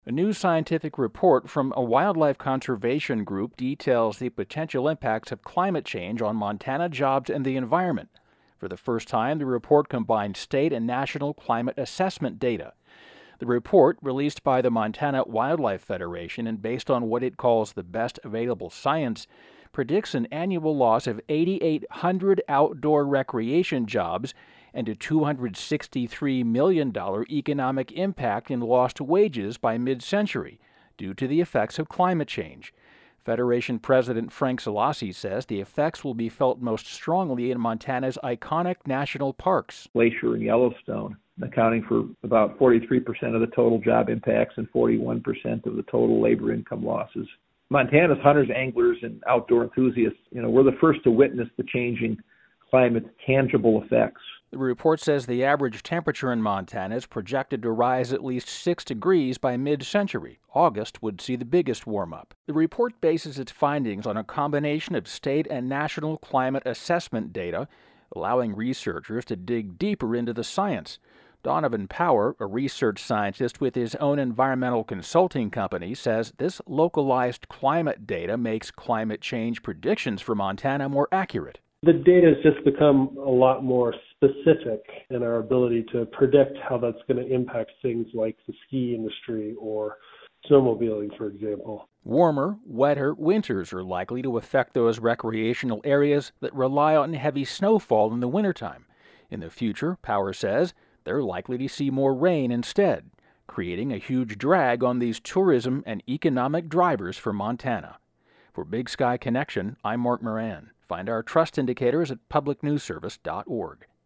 Category: City Desk